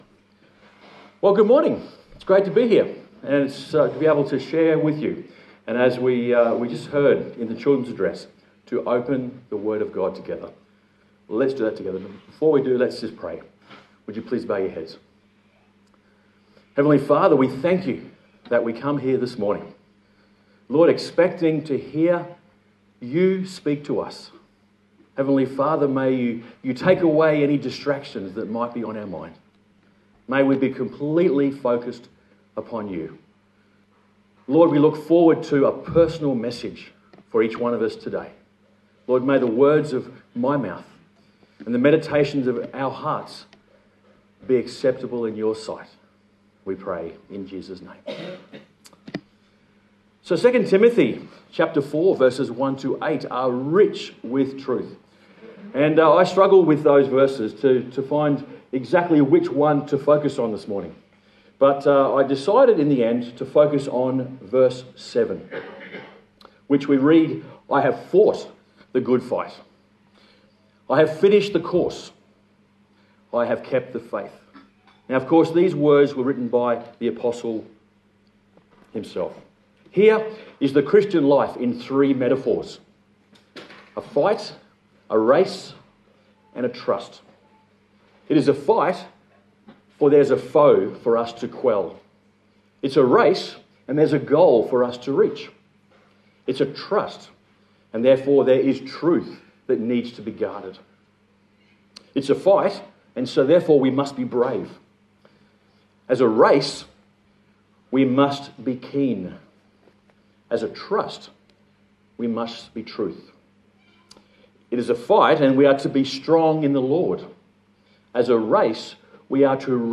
Sunday Service A sermon on the letter 2 Timothy